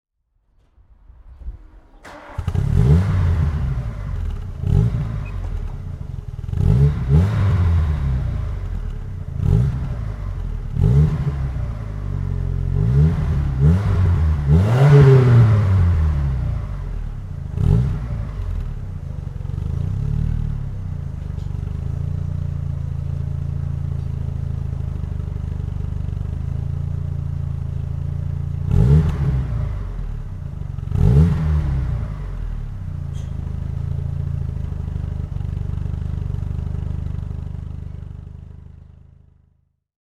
VW_Golf_Cabriolet_1988.mp3